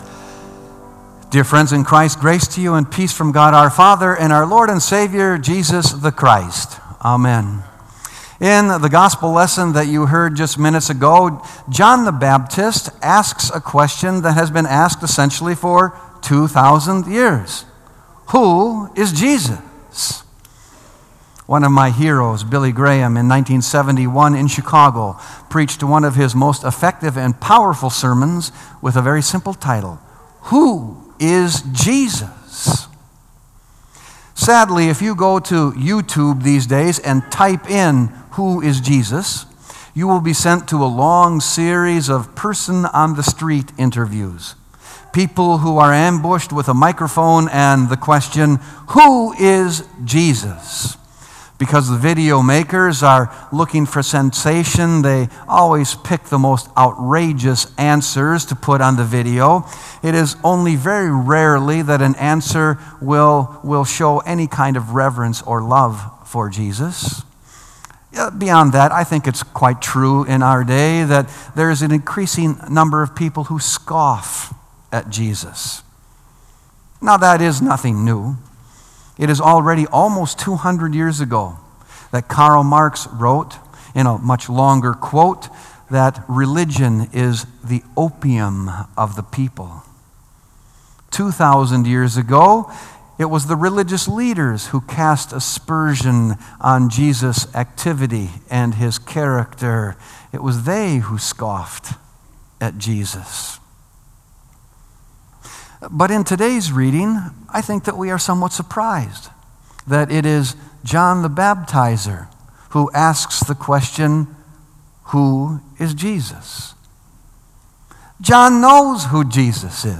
Sermon “Who Are You, Jesus?”